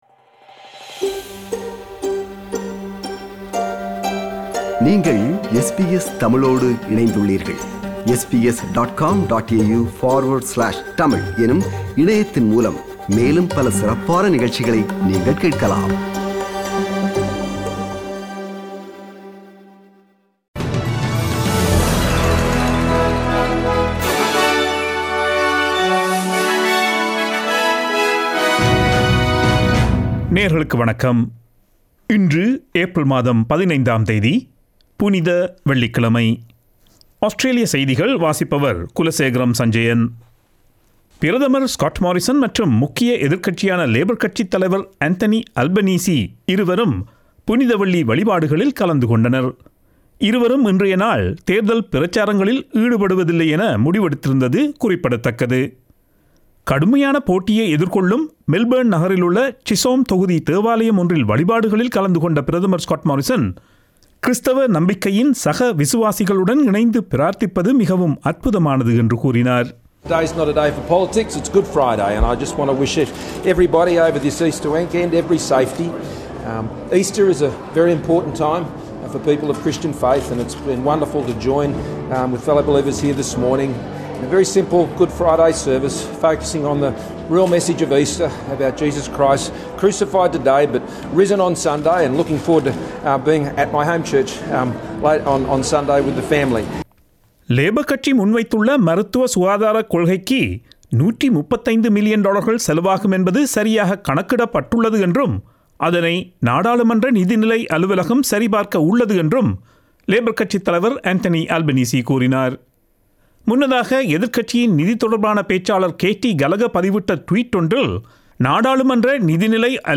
Australian news bulletin for Friday 15 April 2022.